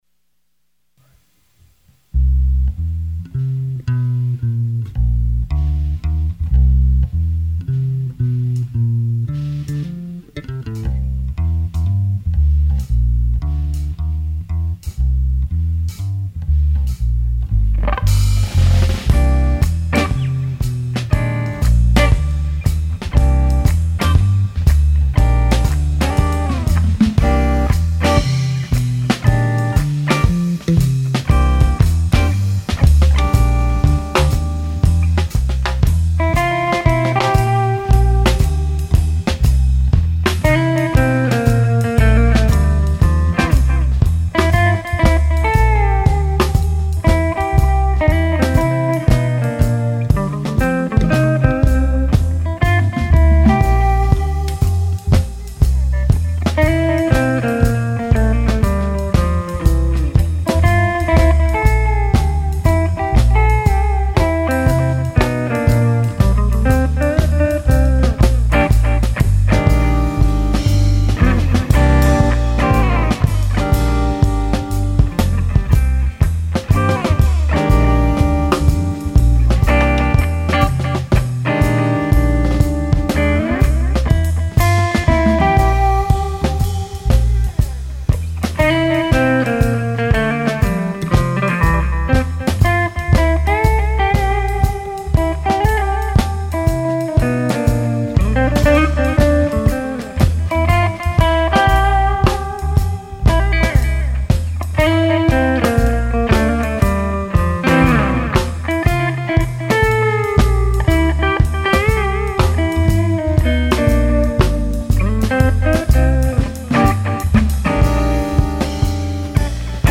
Trio Live